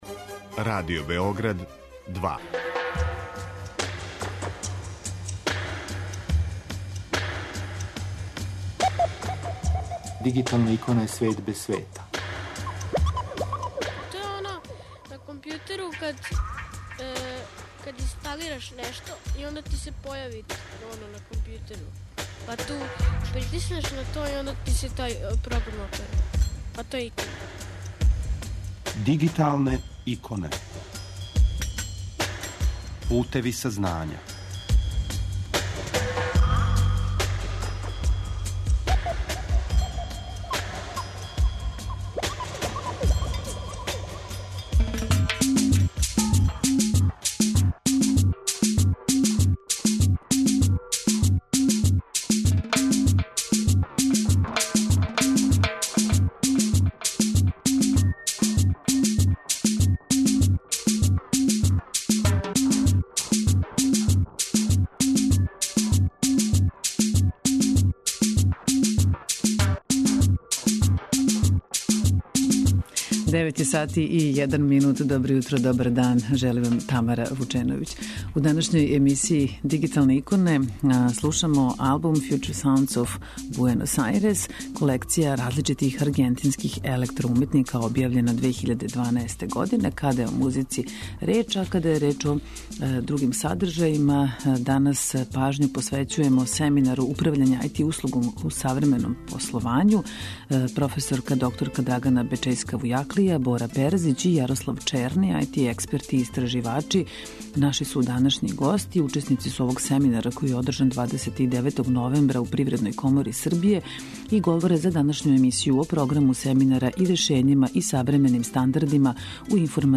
Тим поводом додељена су признања и захвалнице појединцима и установама, а тема нашег разговора су и активности и планови за наредни период, као и изазови са којима се у раду сусрећу.